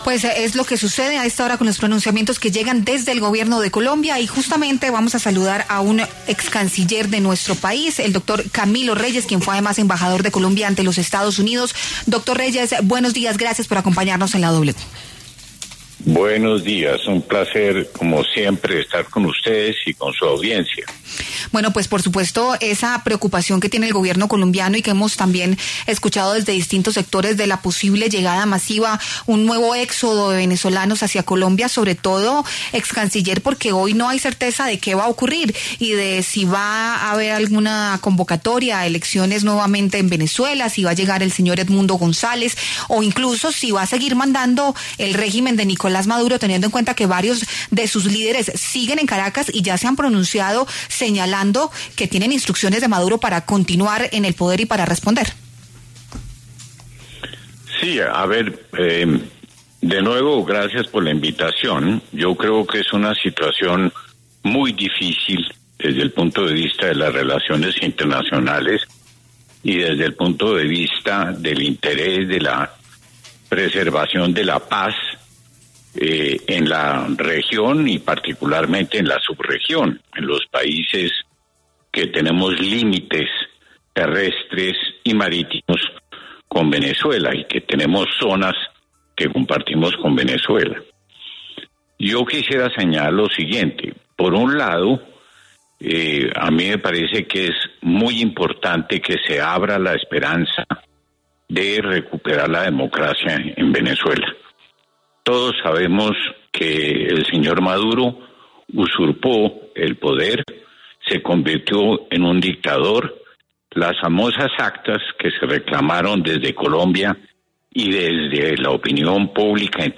Camilo Reyes, excanciller de Colombia, dialogó con La W acerca de la situación política y social en Venezuela luego de que Estados Unidos ingresara al territorio, bombardeara algunos puntos específicos y capturara a Nicolás Maduro.